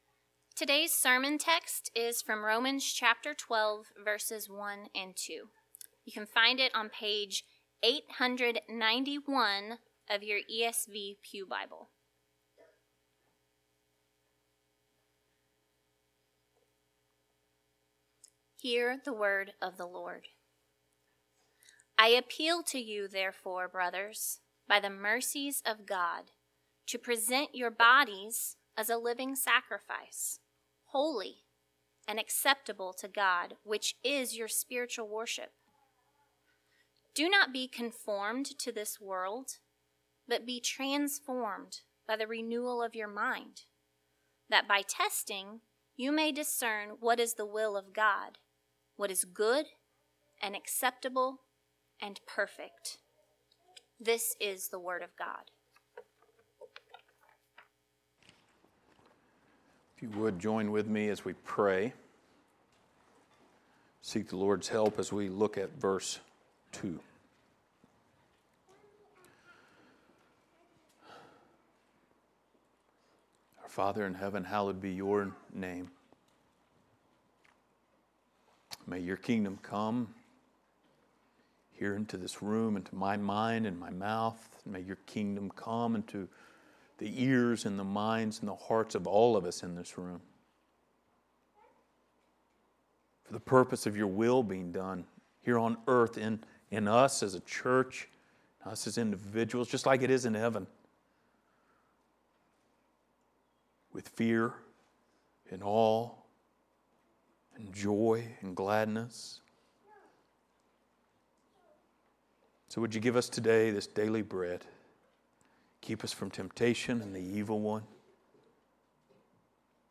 Passage: Romans 12:1-2 Service Type: Sunday Morning